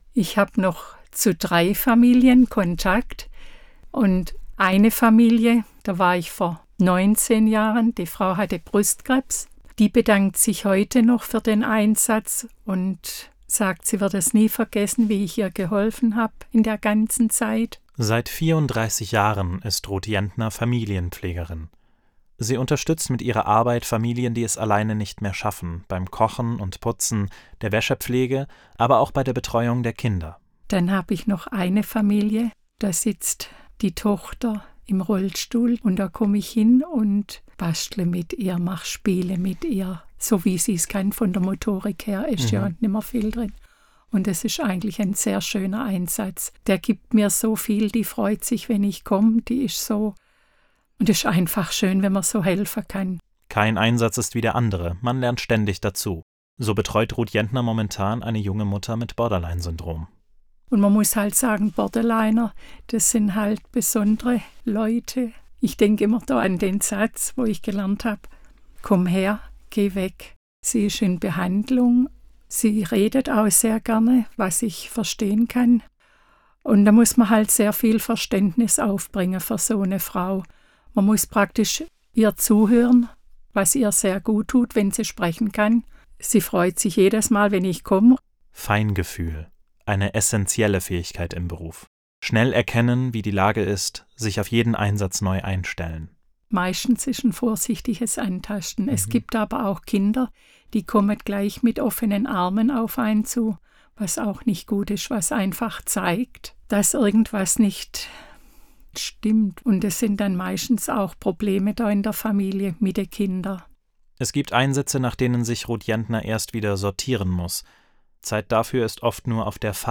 Außerdem finden sich hier Interviews mit ehemaligen Schwestern und heute tätigen Familienpfleger:innen, die von ihren persönlichen Erfahrungen während der Ausbildung und in ihren Einsätzen berichten.